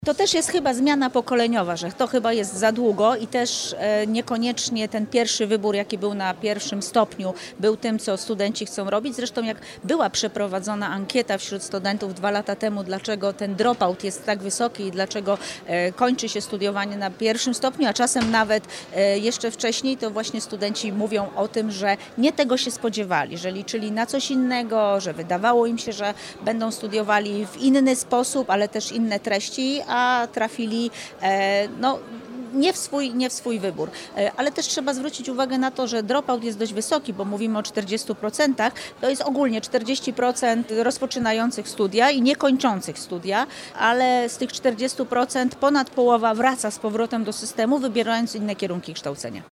Jak dodaje wiceminister, ponad połowa osób, które przerwały naukę, wraca później do systemu szkolnictwa wyższego, wybierając inny kierunek.